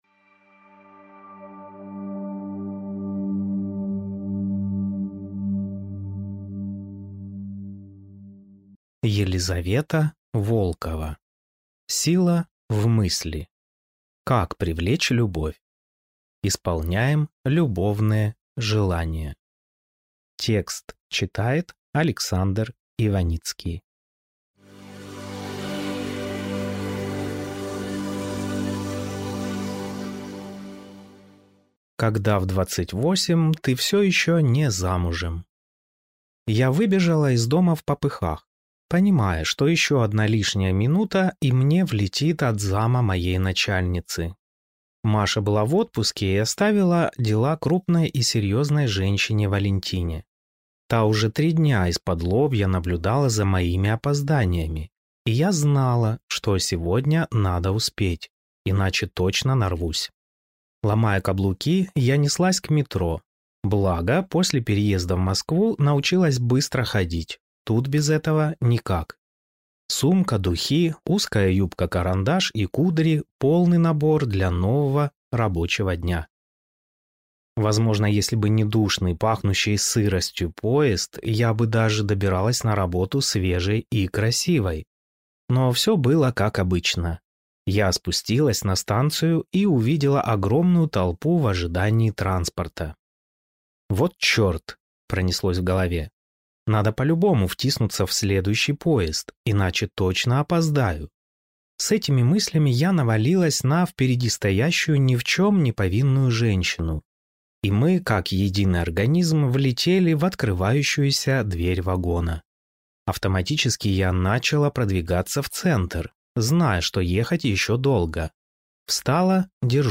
Аудиокнига Сила в Мысли. Как привлечь любовь? Исполняем любовные желания | Библиотека аудиокниг